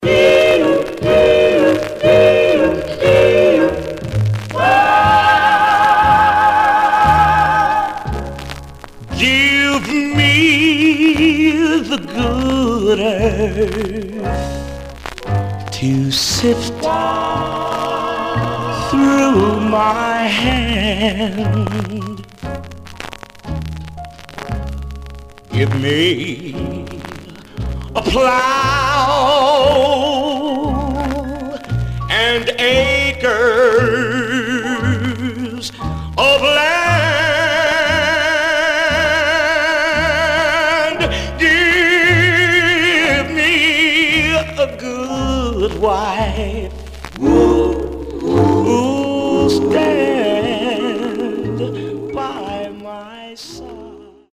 Much surface noise/wear Stereo/mono Mono
Male Black Groups